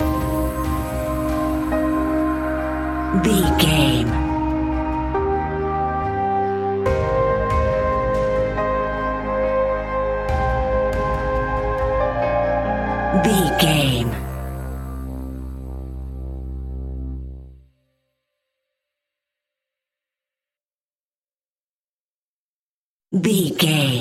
In-crescendo
Thriller
Aeolian/Minor
ominous
haunting
eerie
piano
strings
synthesiser
percussion
brass
horror music